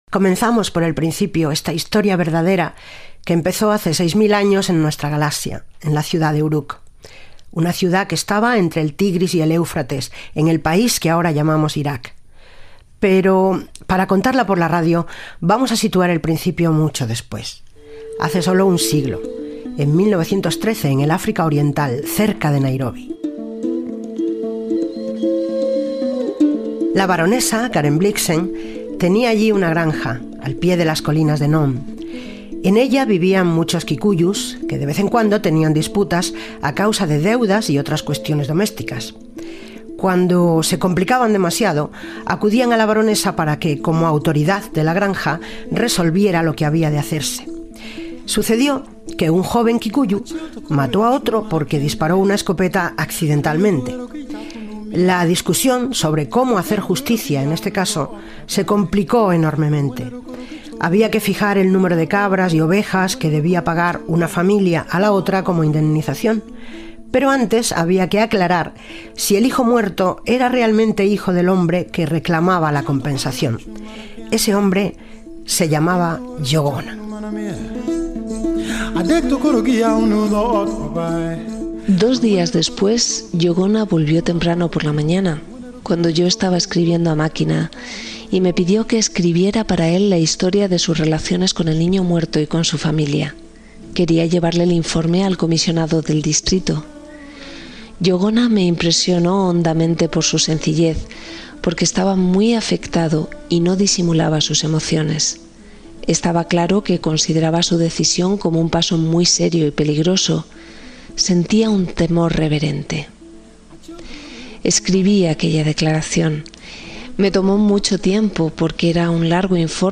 Radio Nacional de España Barcelona